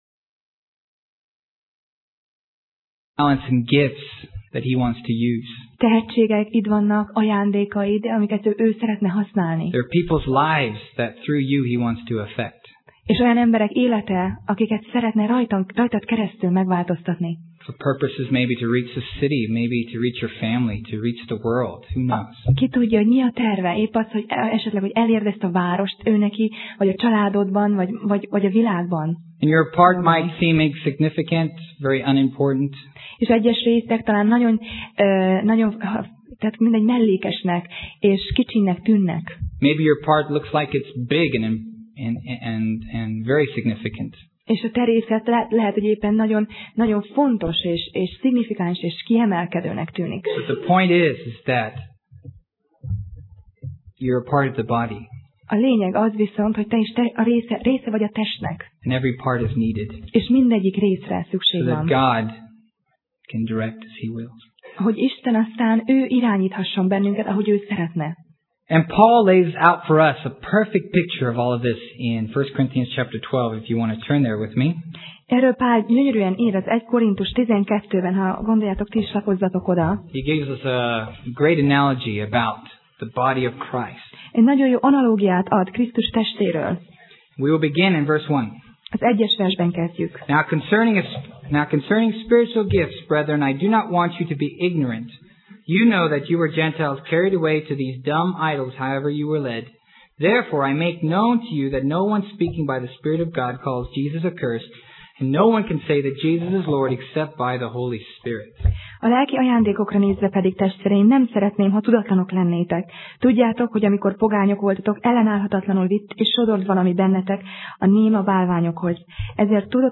Sorozat: Tematikus tanítás Passage: 1Korinthus (1Cor) 12 Alkalom: Vasárnap Reggel